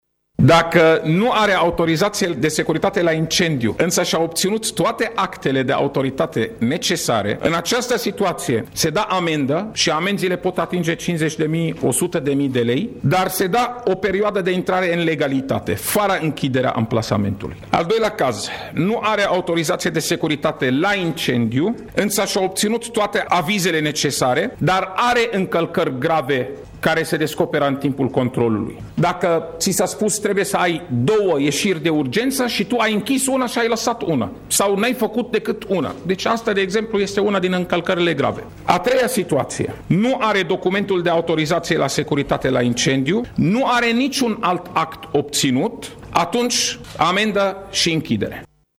O ordonanţă de urgenţă în acest sens a fost adoptată, astăzi, în şedinţa de Guvern. Secretarul de stat, Raed Arafat precizează că planul de controale este definitivat şi va fi pus în aplicare după publicarea actului normativ în Monitorul Oficial :